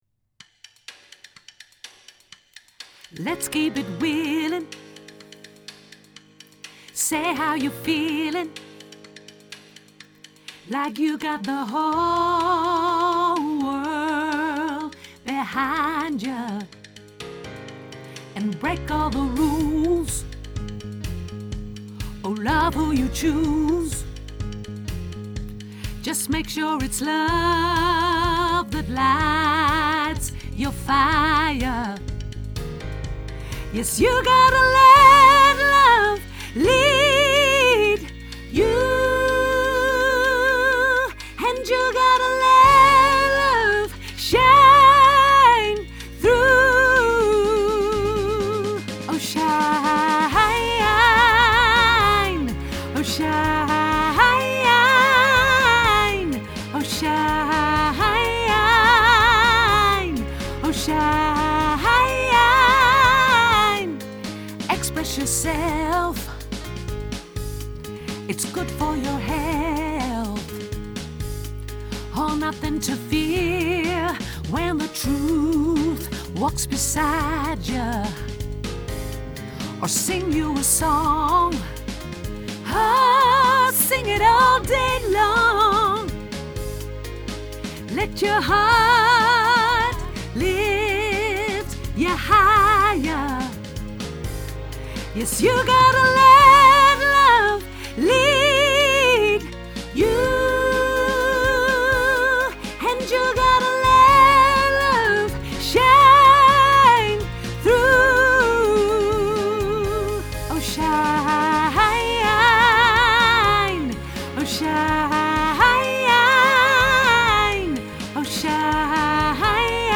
sopraan mezzo